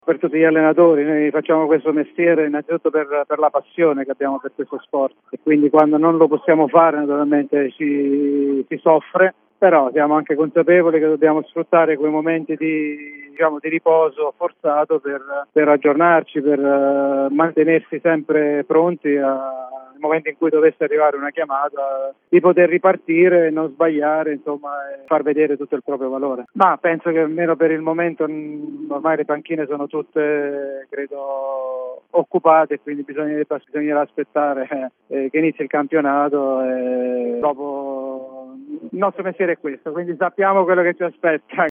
Ospite di Radio Punto Nuovo, nel corso di Punto Nuovo Sport, l’ex tecnico dell’Avellino, Massimo Rastelli (ancora sotto contratto con i lupi fino a 30 giugno 2025) ha parlato del suo futuro.